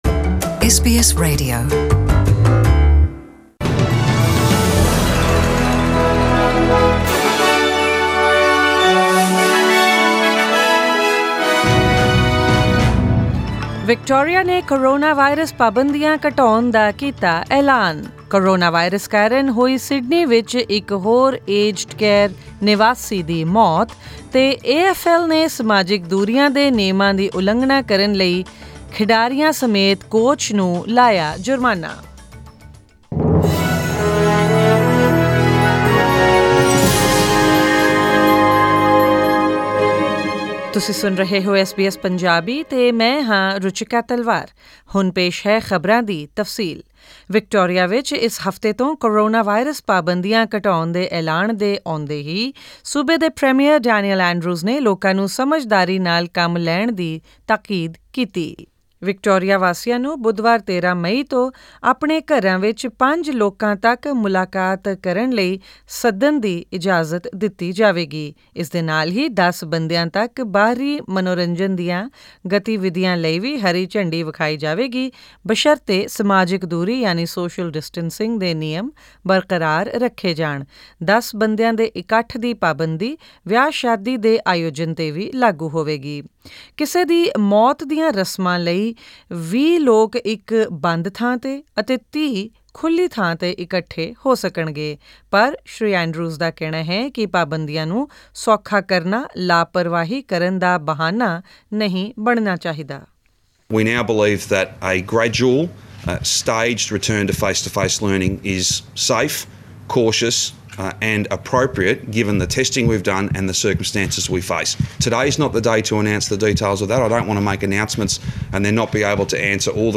Australian News in Punjabi: 11 May 2020